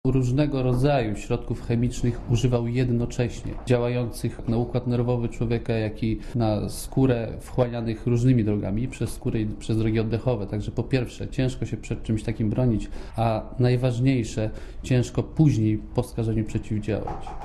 Komentarz audio (136Kb)